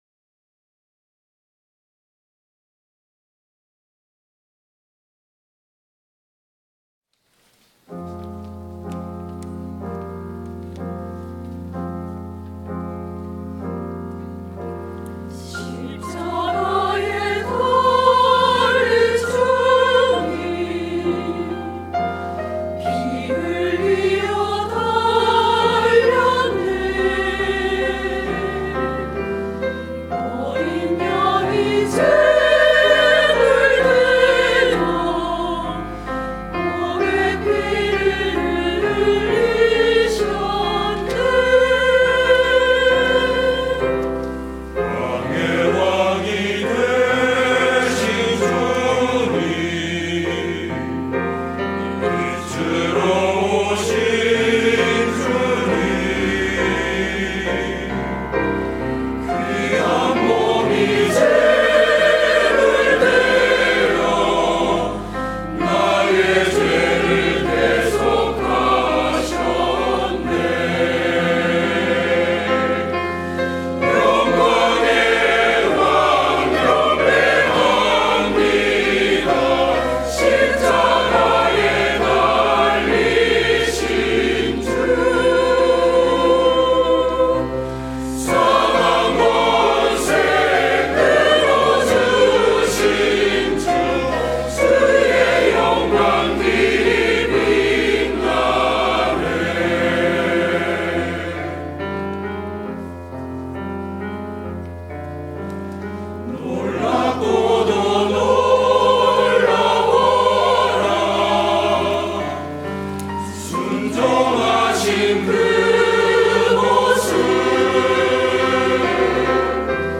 갈릴리